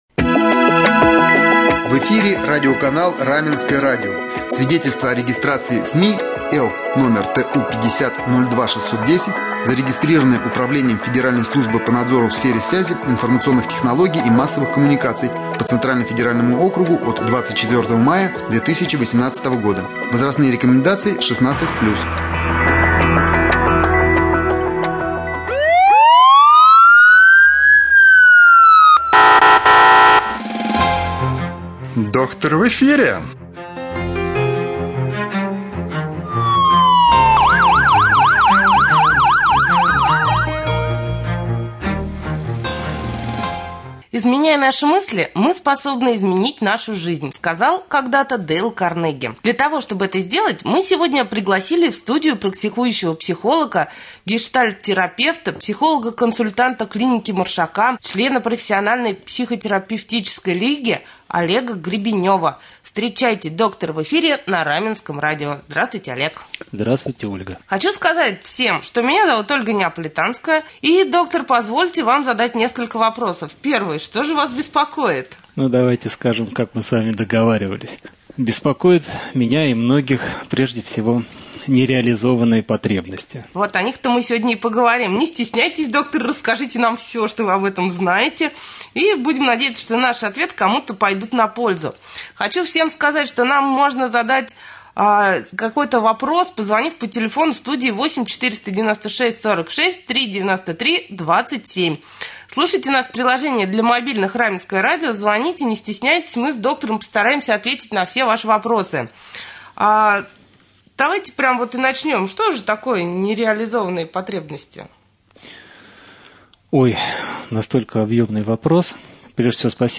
Гость студии — практикующий психолог, гештальт-терапевт